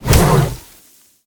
Sfx_creature_pinnacarid_flinch_land_02.ogg